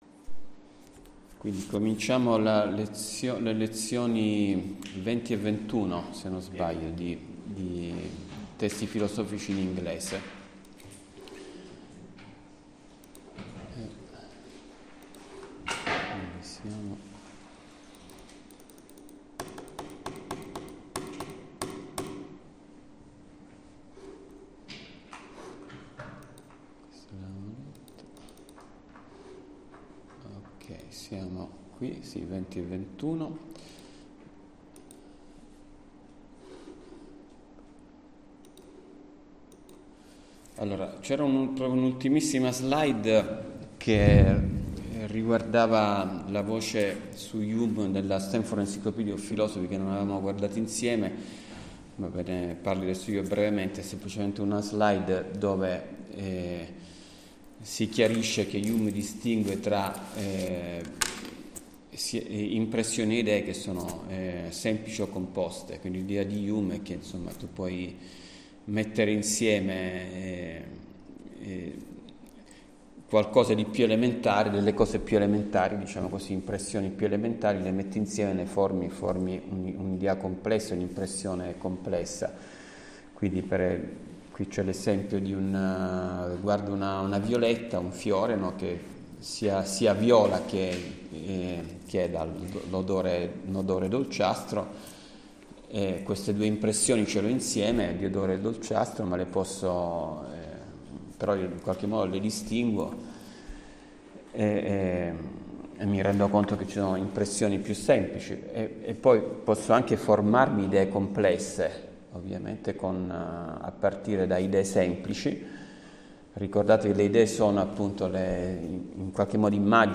audio lezioni 25 novembre [MP3 audio - 63.2 MB]